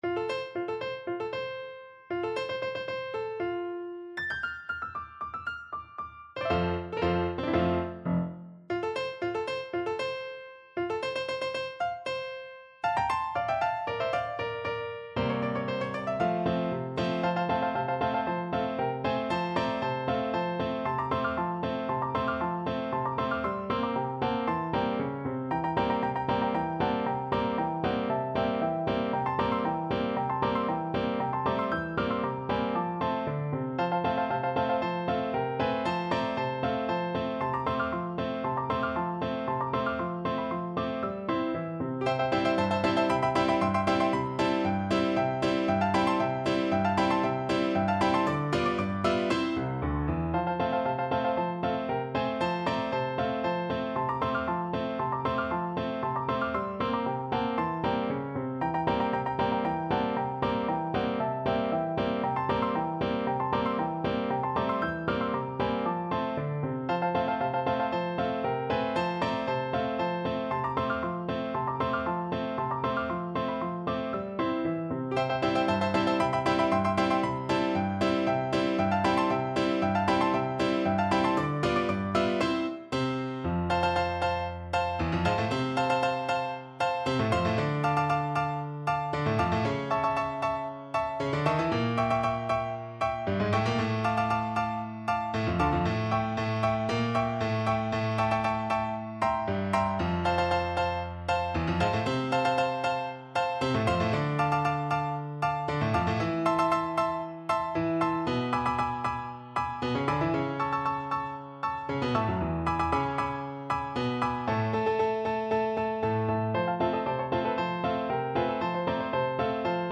Tempo di Marcia (=116)
2/4 (View more 2/4 Music)
Piano  (View more Advanced Piano Music)
Classical (View more Classical Piano Music)